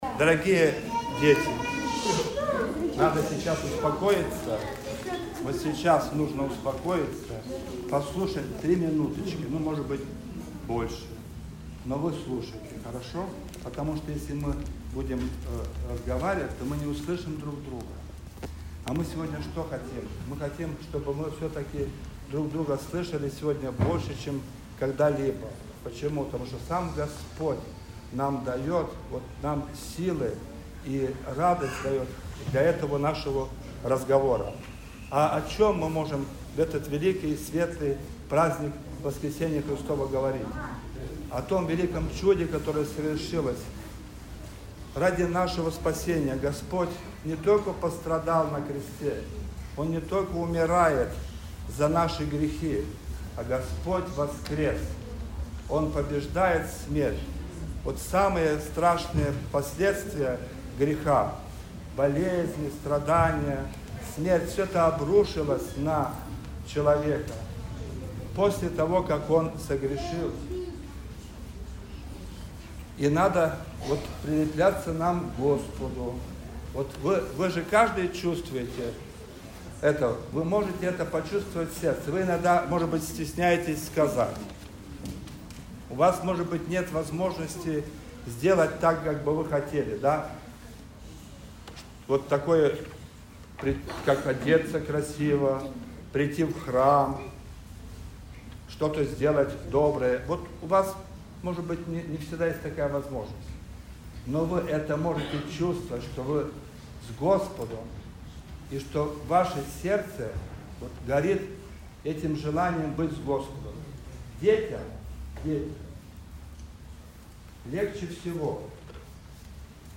20 апреля 2025 года, в праздник Светлого Христова Воскресения, в храме святого праведного Иоанна Кронштадтского на Кронштадтской площади была совершена Пасхальная вечерня и традиционный детский Пасхальный крестный ход.
Проповедь